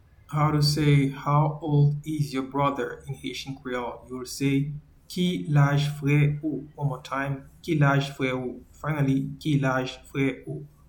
Pronunciation:
How-old-is-your-brother-in-Haitian-Creole-Ki-laj-fre-ou.mp3